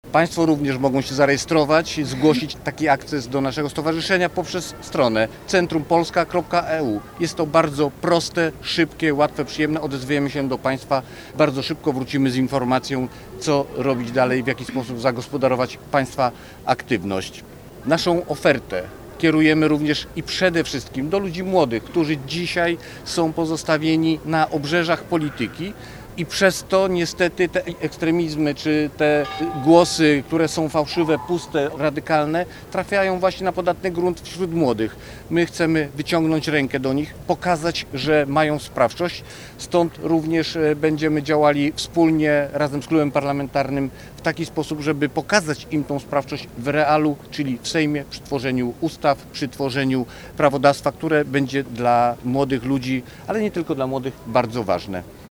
-Można już do nas dołączyć – zaprasza poseł na Sejm – Rafał Kasprzyk,